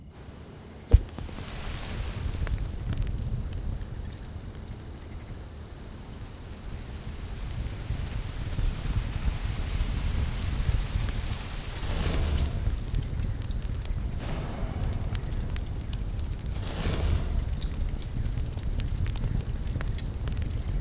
环境音
火灾演出.wav